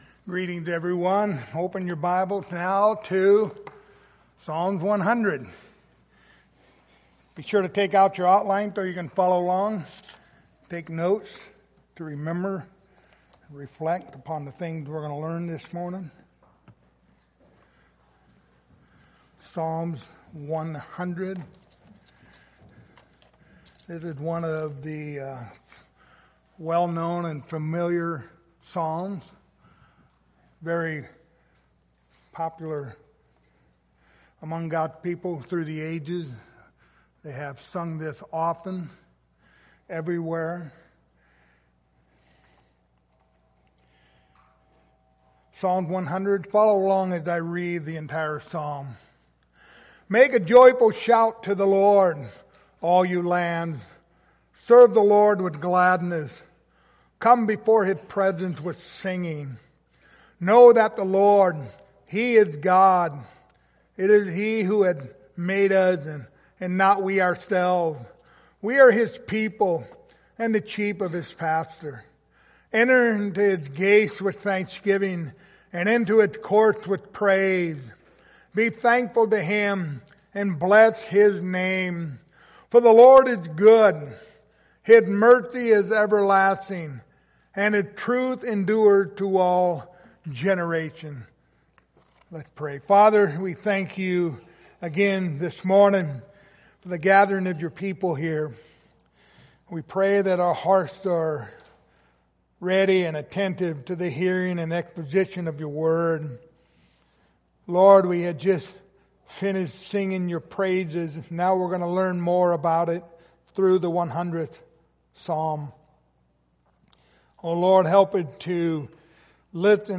The book of Psalms Passage: Psalms 100:1-5 Service Type: Sunday Morning Topics